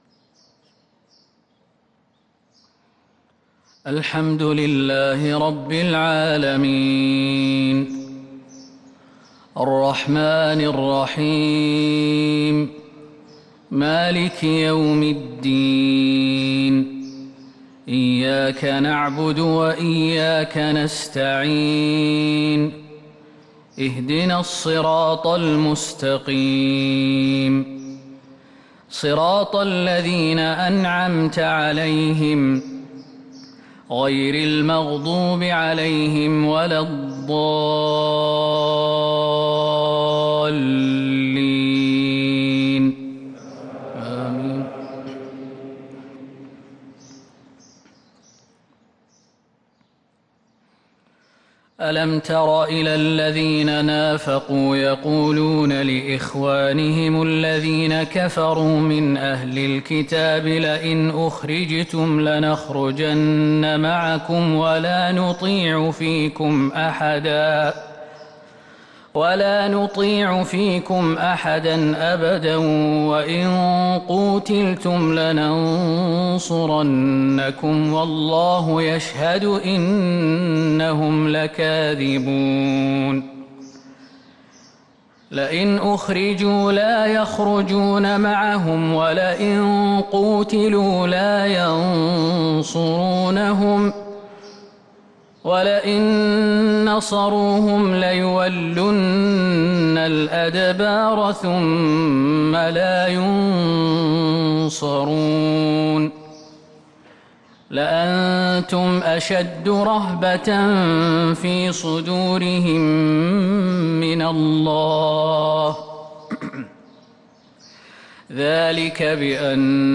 فجر الأربعاء 5 رمضان 1443هـ خواتيم سورة الحشر | Fajr Prayer from Surah Al-Hashr 6-4-2022 > 1443 🕌 > الفروض - تلاوات الحرمين